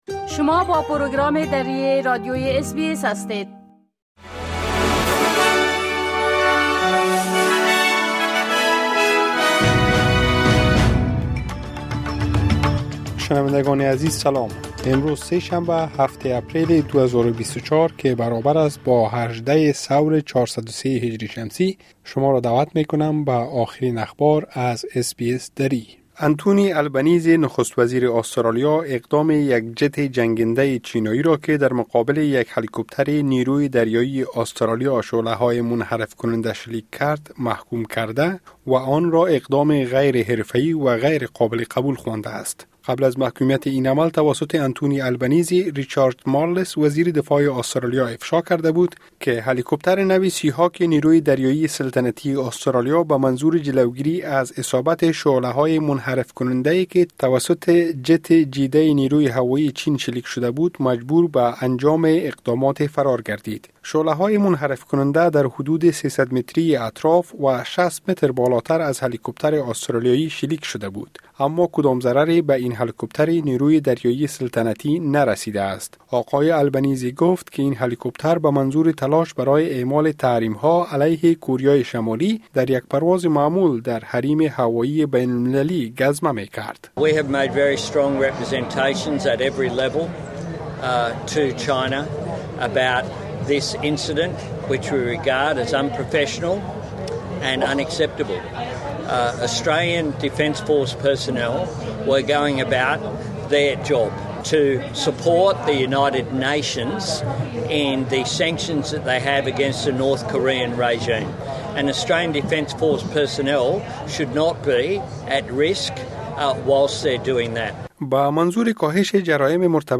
مهمترين اخبار روز از بخش درى راديوى اس بى اس|۷ می ۲۰۲۴